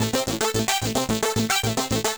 Index of /musicradar/8-bit-bonanza-samples/FM Arp Loops
CS_FMArp B_110-A.wav